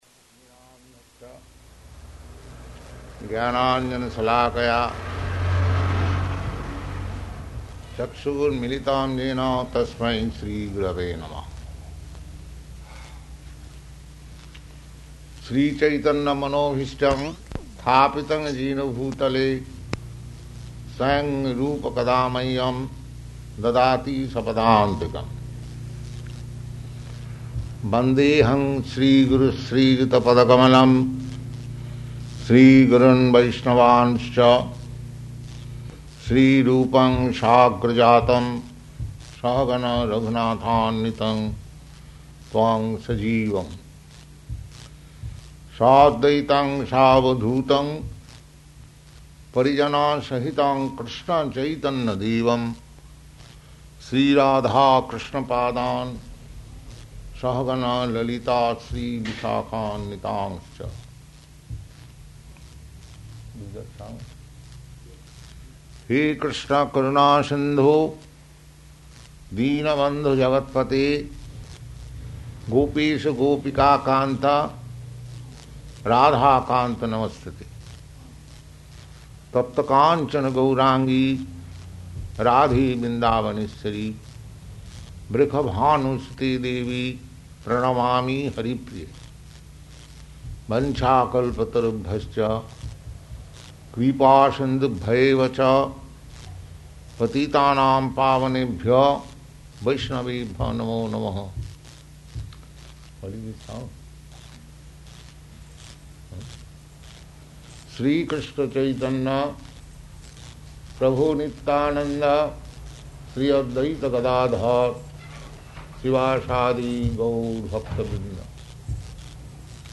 Type: Srimad-Bhagavatam
Location: San Francisco
Prabhupāda: [chants maṅgalācaraṇa prayers; indistinct aside comments to devotee]